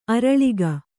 ♪ araḷiga